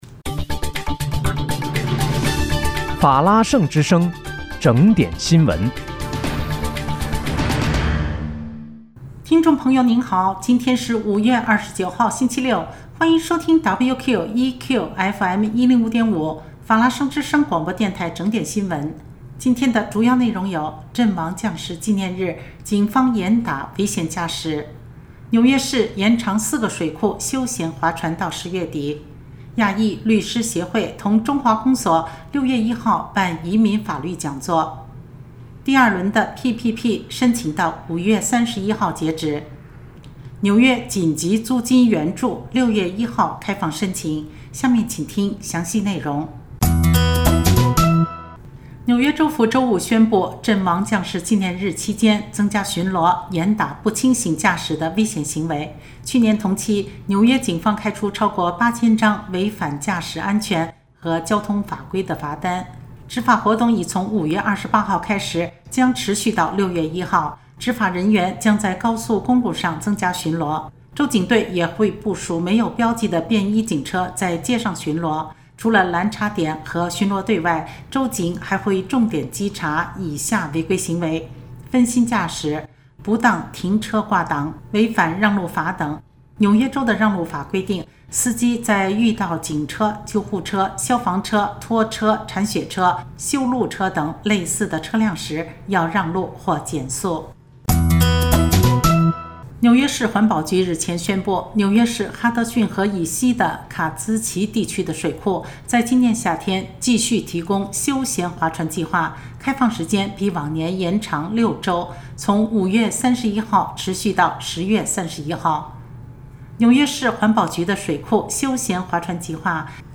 5月29日（星期六）纽约整点新闻
听众朋友您好！今天是5月29号，星期六，欢迎收听WQEQ105.5法拉盛之声广播电台整点新闻。